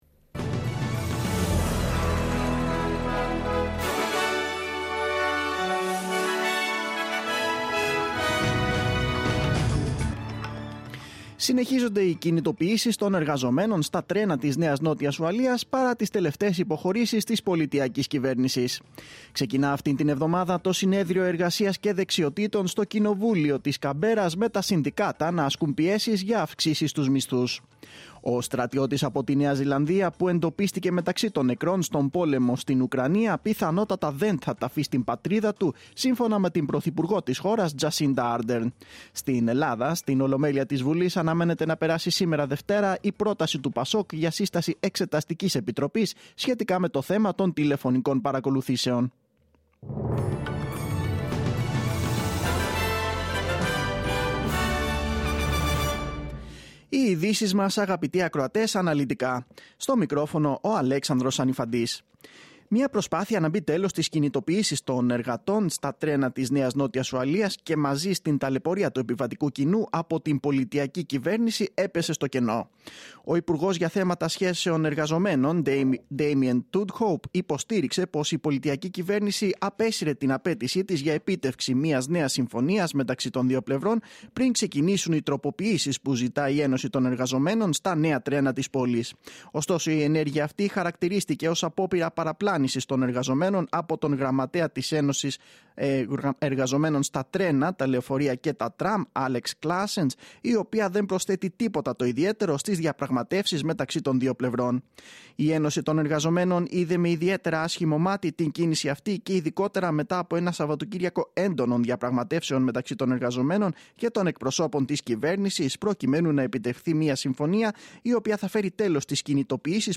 Το αναλυτικό δελτίο ειδήσεων από το Ελληνικό Πρόγραμμα της ραδιοφωνίας SBS, στις 4 μμ.
News in Greek.